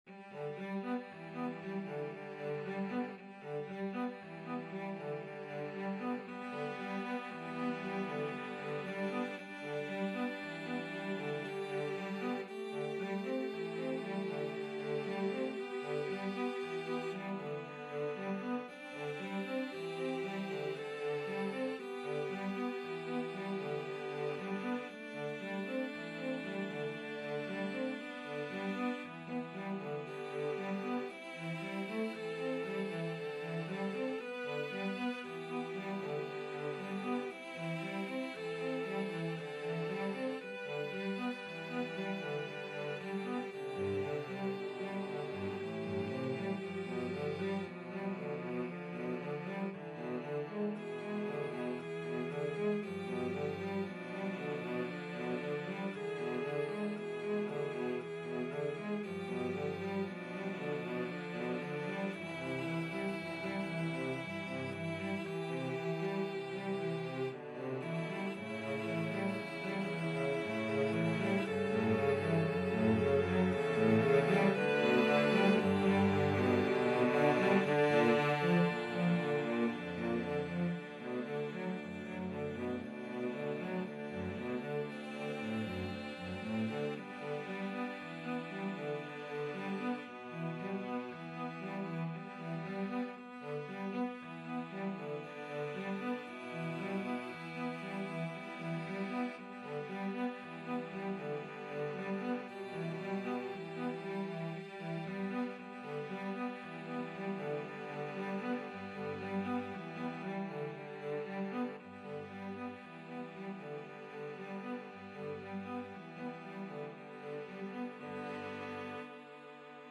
3/4 (View more 3/4 Music)
Cello Quartet  (View more Intermediate Cello Quartet Music)
Classical (View more Classical Cello Quartet Music)